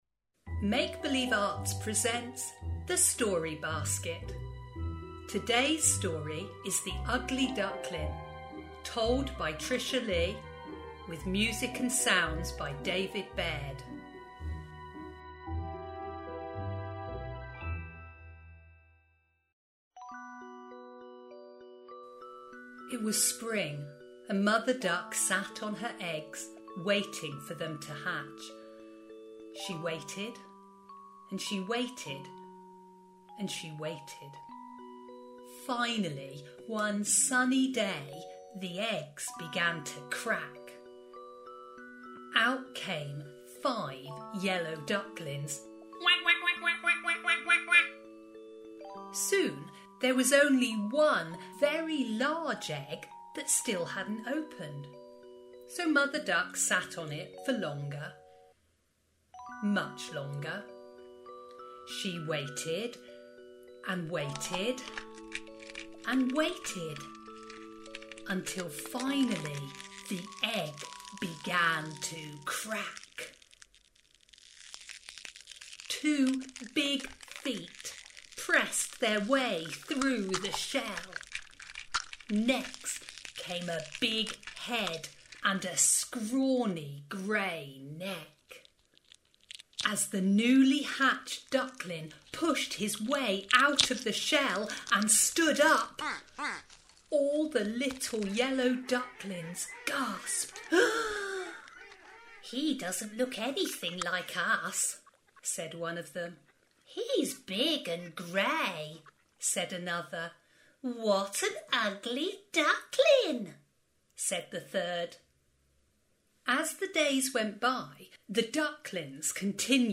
The Story Basket contains 27 audio-only fairy tales and folktales, accompanied by music and sound to bring these magical stories to life.